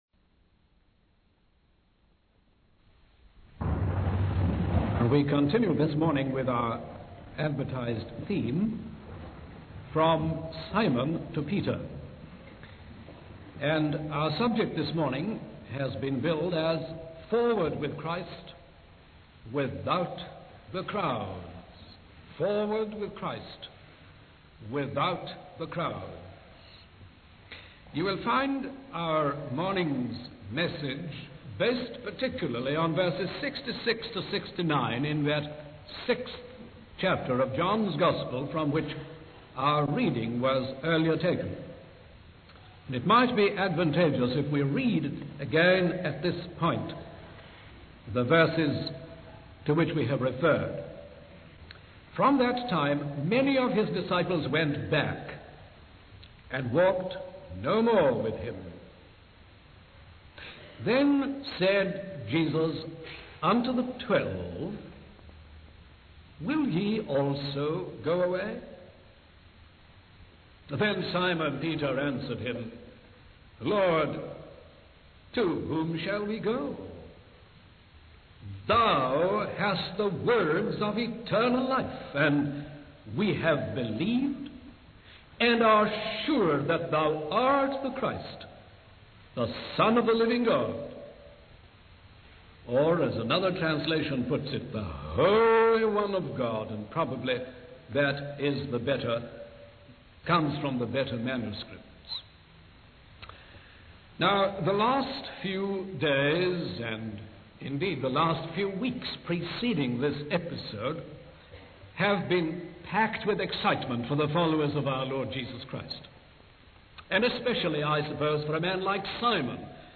In this sermon, the preacher focuses on the retreating multitudes, the resolute Lord, and the remnant few left. He describes how many of Jesus' disciples turned away from him because they were more interested in what they could gain from him rather than truly seeking him.